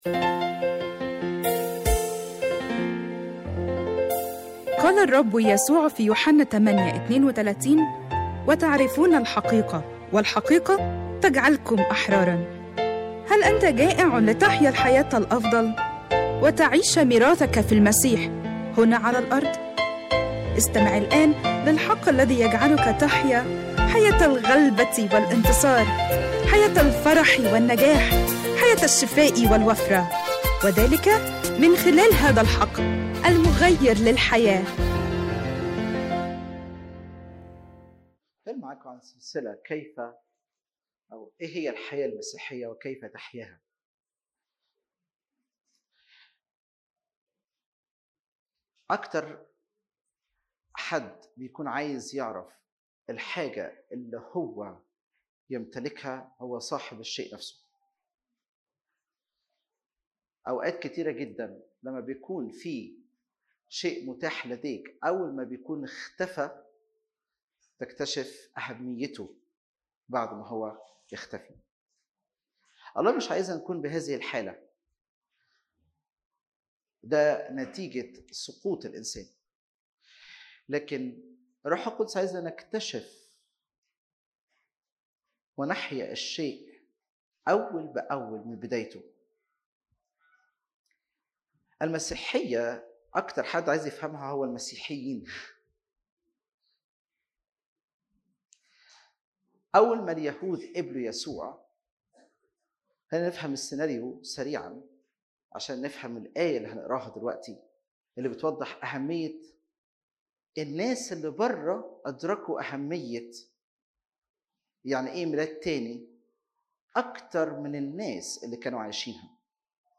اجتماع الثلاثاء 2/3/2021 السلسلة مكونه من 8 أجزاء